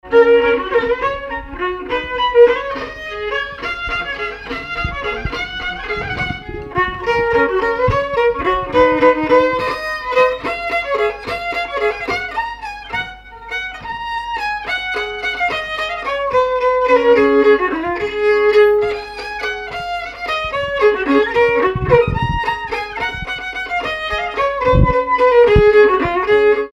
danse : valse
circonstance : bal, dancerie
Pièce musicale inédite